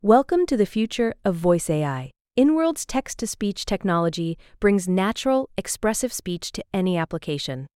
multilingual text-to-speech voice-cloning
Highest-quality text-to-speech with <200ms latency, emotion control, and 15-language support